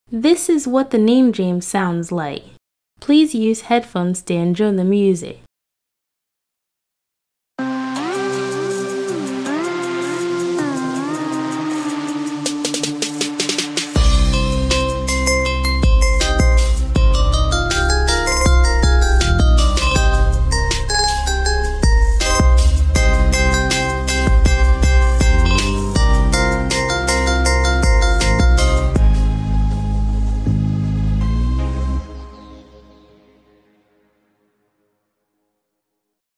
as midi art